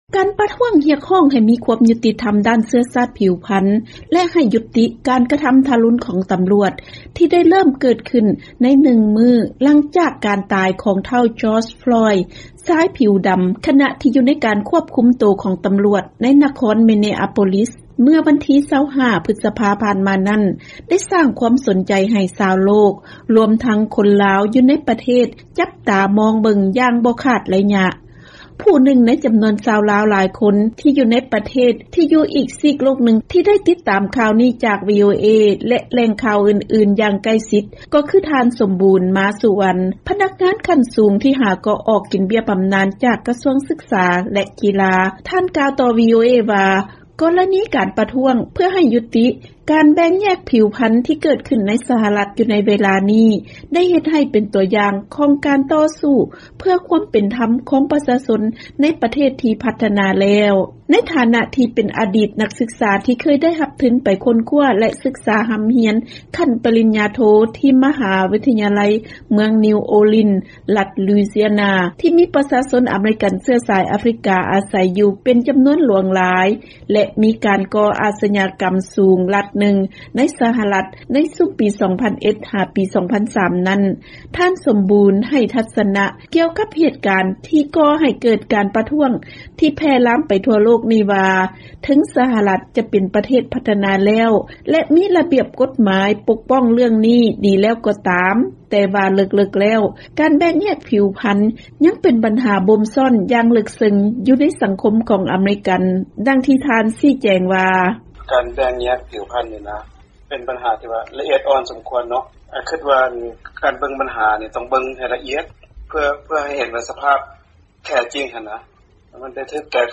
ເຊີນຟັງຄໍາຄິດເຫັນຂອງຄົນລາວຜູ້ນຶ່ງກ່ຽວກັບການປະທ້ວງເພື່ອຍຸຕິການແບ່ງແຍກຜິວພັນໃນ ສຫລ